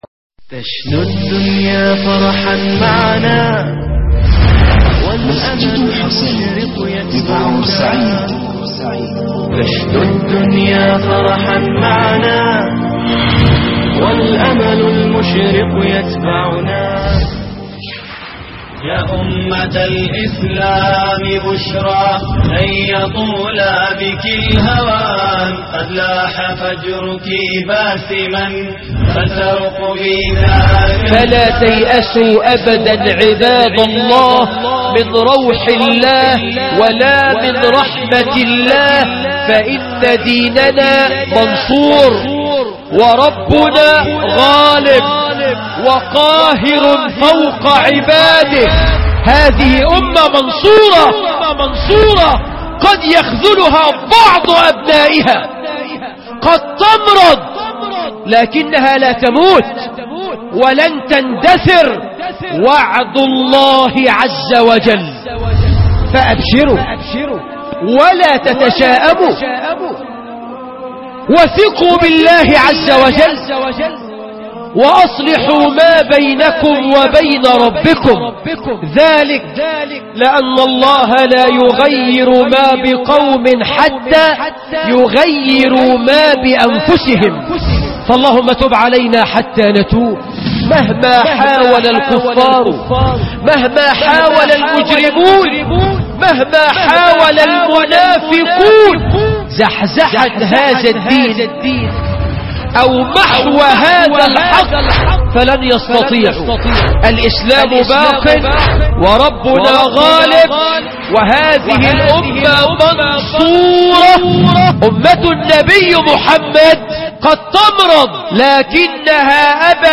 مبشرات النصر والتمكين- خطب الجمعه